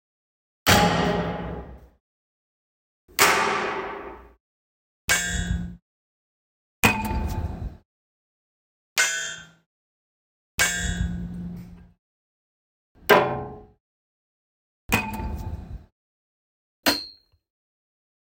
Multiple Pipe Banging Metal Hit Sound Effects
bang banging clang collision crash hammer hit impact sound effect free sound royalty free Tiktok Trends